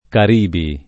kar&bi] o caraibi [kar#ibi; antiq. kara&bi] etn. pl. — incerta, e quasi solo teorica, la forma del sing. (caribe alla sp.? cariba? caribo? o il pur di rado attestato caraibo [antiq. kara&bo]?) — d’uso oggi com. solo il pl. Caraibi quale top. approssimativo per «Mar Caribico» o «isole caribiche»; e in uso, oggi, con l’accentaz. kar#ibi, difficile a giustificarsi ma attestata come di fatto unica già dal Vanzon (1830), dal «Panlessico» (1839) e dal Tommaseo (1865)